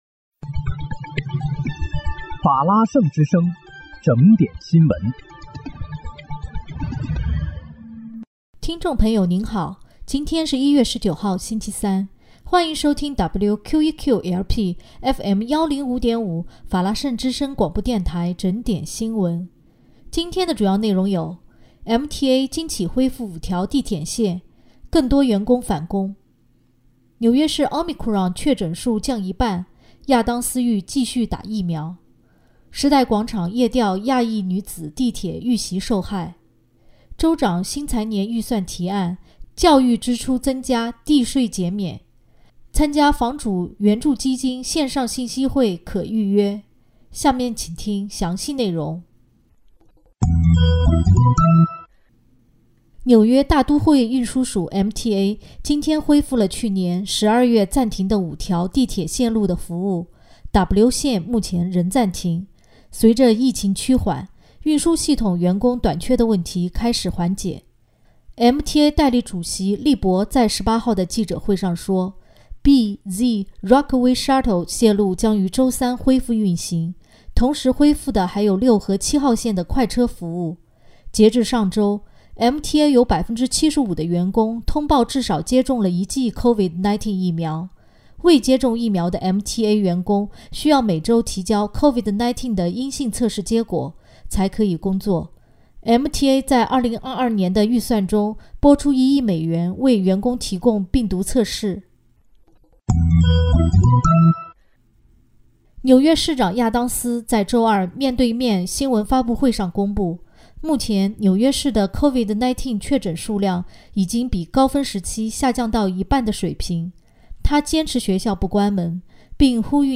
1月19日（星期三）纽约整点新闻